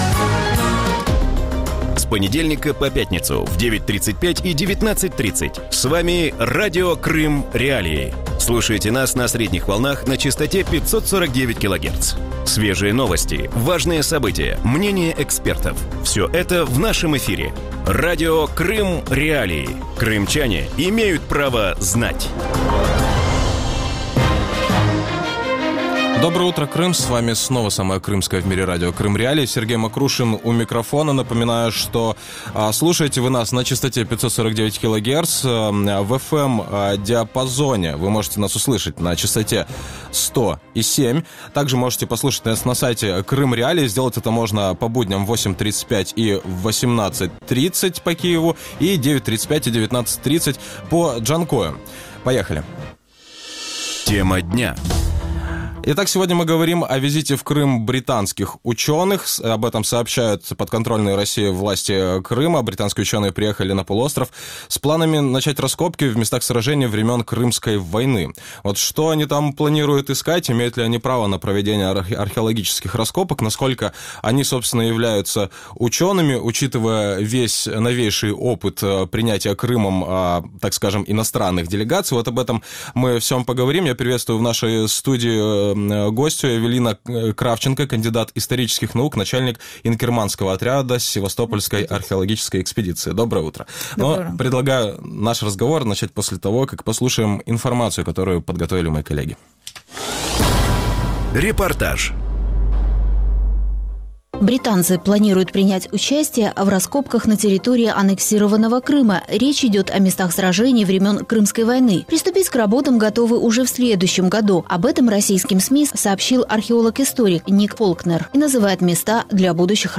Утром в эфире Радио Крым.Реалии говорят о визите в Крым британских ученых.